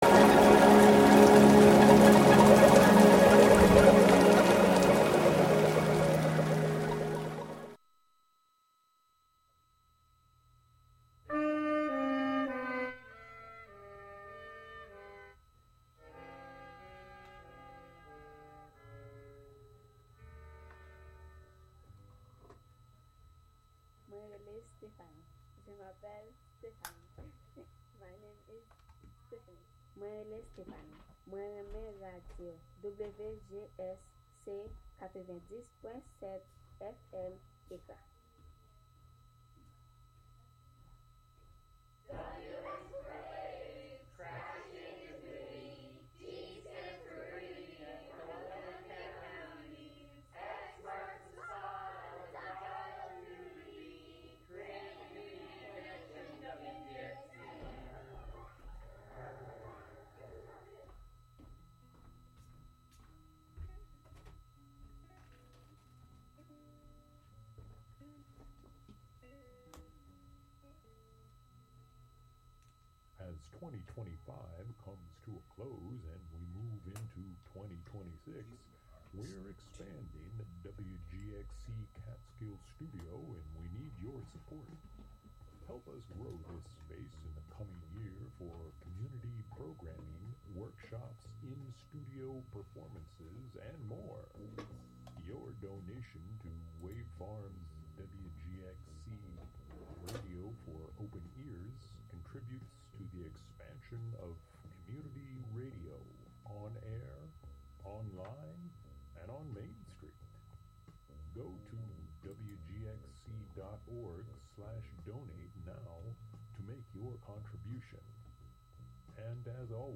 American Tarragon brings you the blissed out sounds of folk, ambient, rock-n-roll, psychedelia, electronica, new age, world music, and beyond.